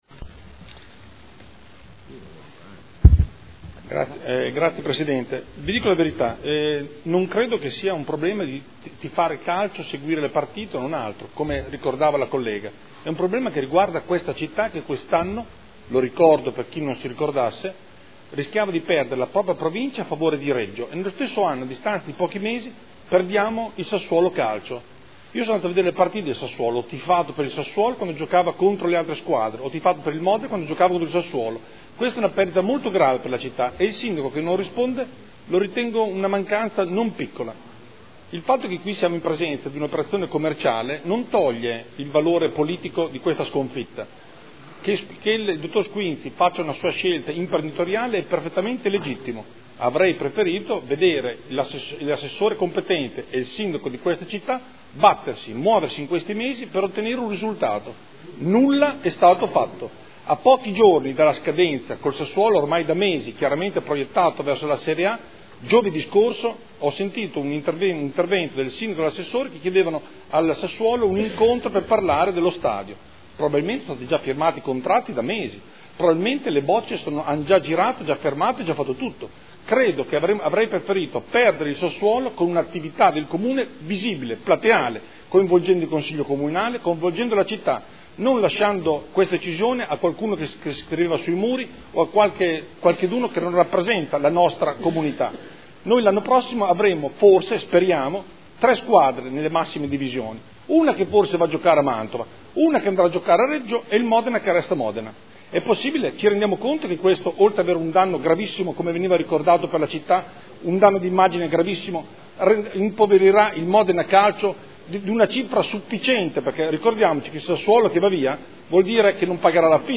Andrea Galli — Sito Audio Consiglio Comunale
Seduta del 03/06/2013. Dibattito su interrogazione urgente dei consiglieri Galli e Morandi (PdL) avente per oggetto: “L’Amministrazione comunale di Modena durante le partite di calcio ha fatto prestare servizio di ordine pubblico ai Vigili Urbani del Comune di Sassuolo e/o si è fatta ristornare dal Comune di Sassuolo le spese sostenute – Forse il Comune ha fatto bene, in un’ottica “sparagnina” tipica di questa Amministrazione; ma perchè non ha fatto pagare gli stessi servizi per decenni ad organizzazioni private come, ad esempio, la “Festa dell’Unità”?”